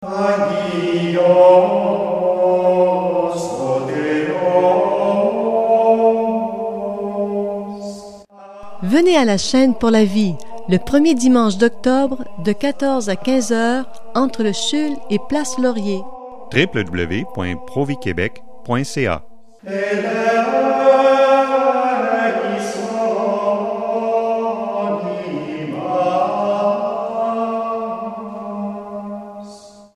Il y a aussi un petit message publicitaire normalement utilisé à la radio: